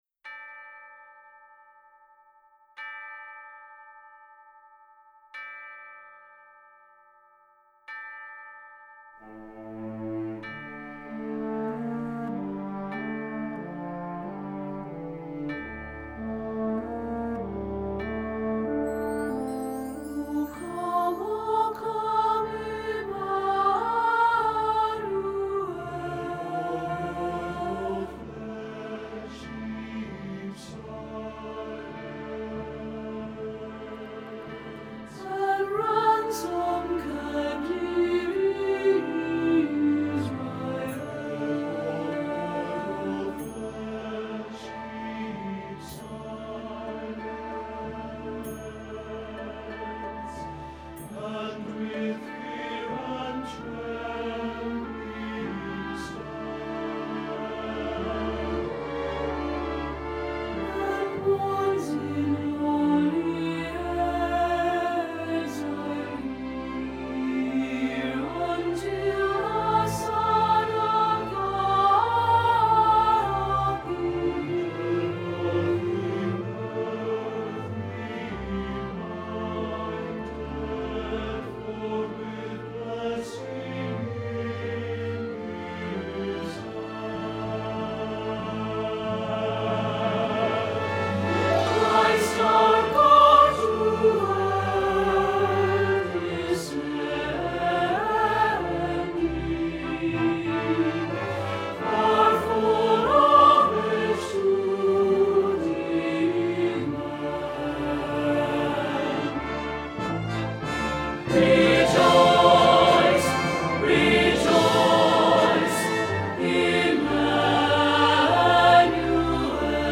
Christmas Cantata 2024 - Alto — Dutilh Church